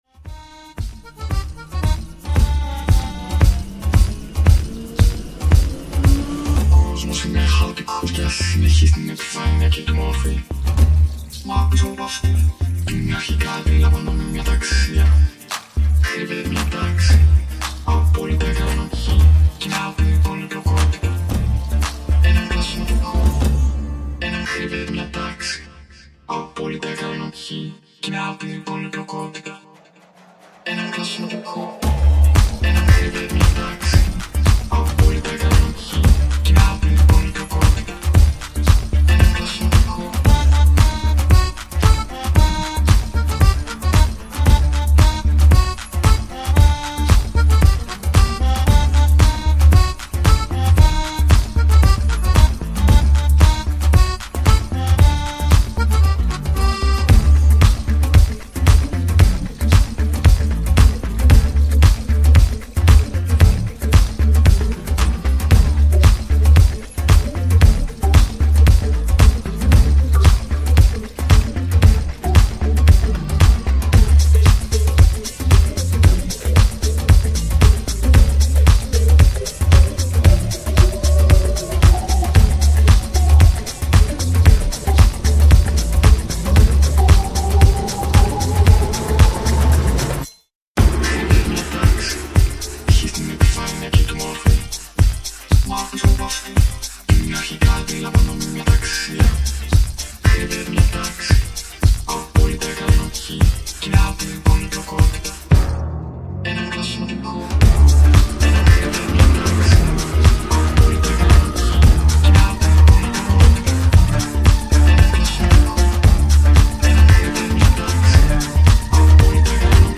Disco House